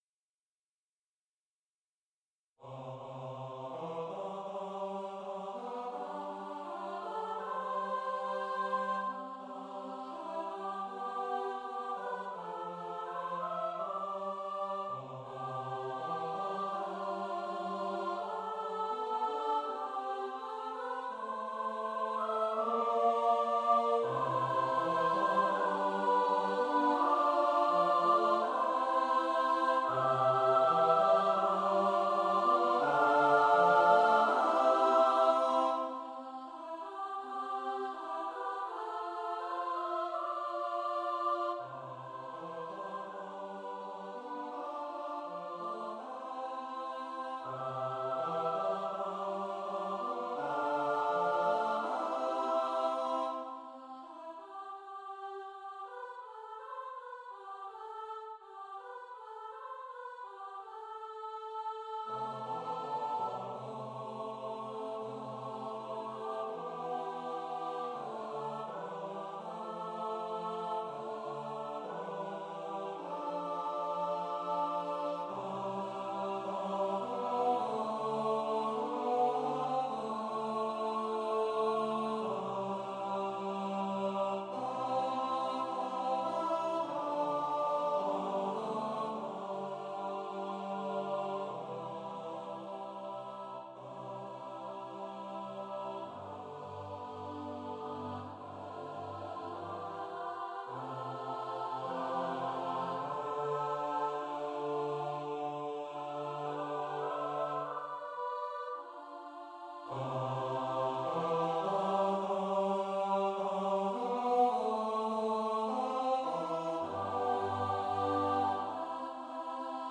An a cappella piece for SATB.